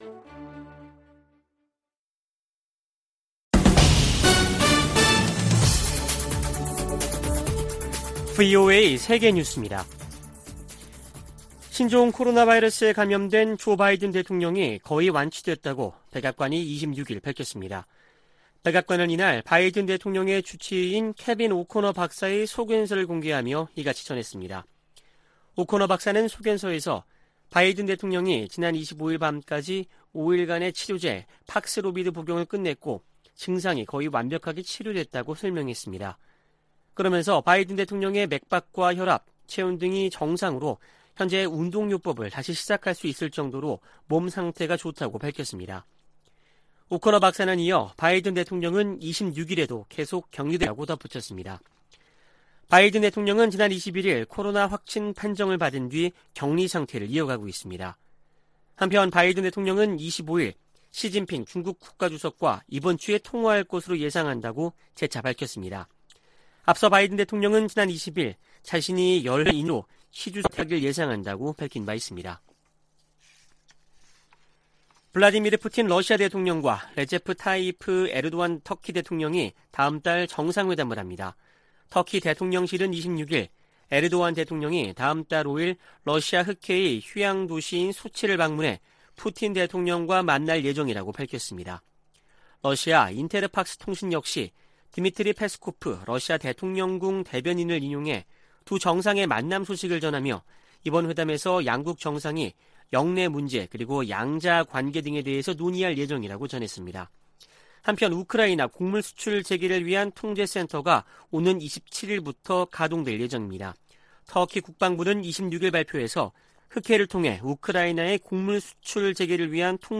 VOA 한국어 아침 뉴스 프로그램 '워싱턴 뉴스 광장' 2022년 7월 27일 방송입니다. 미 국무부는 모든 가용한 수단을 동원해 북한 악의적 사이버 공격 세력을 추적하고 있다고 밝혔습니다. 아미 베라 미 하원의원은 북한의 핵실험을 한일 갈등 극복과 미한일 관계 강화 계기로 삼아야 한다고 말했습니다. 미군과 한국 군이 세계 최강 공격헬기를 동원한 훈련을 실시했습니다.